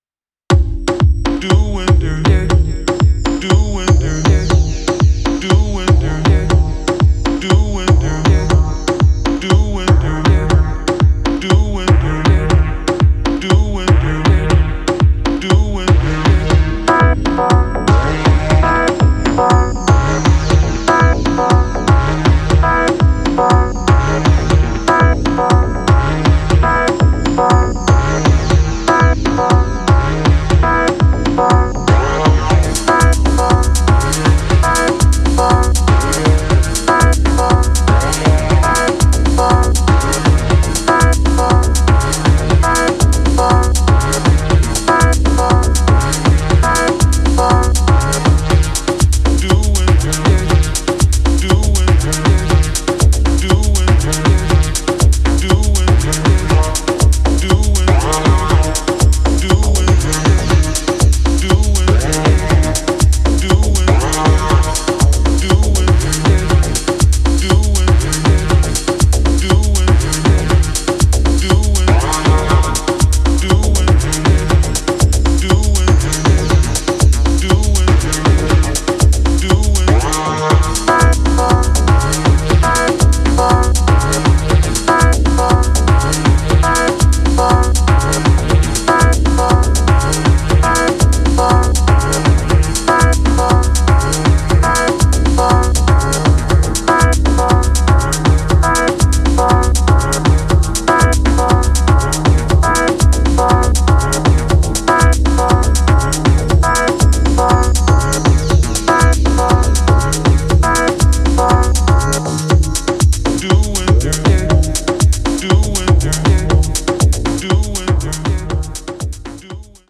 UKファンキーを遅くしたようなトライバルなドラムに催眠的なヴォイスサンプルとオルガンコードをシンプルに配置した